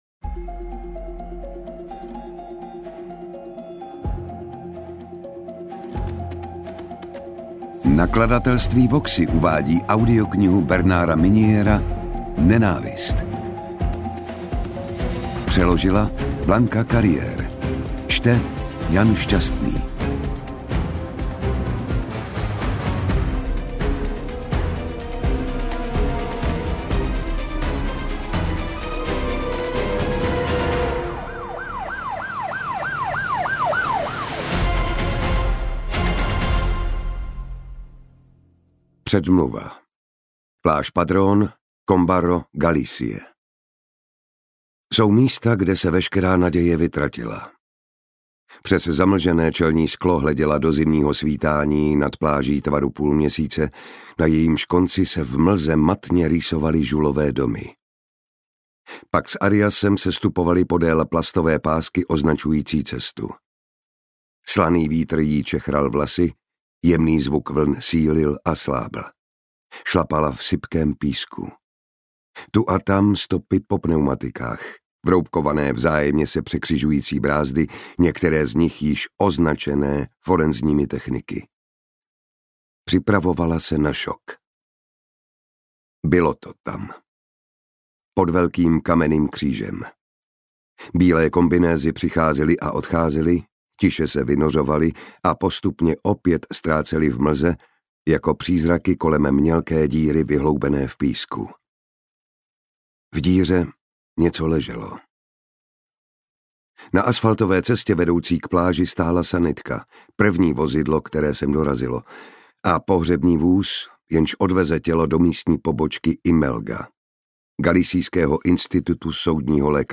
Audiobook
Read: Jan Šťastný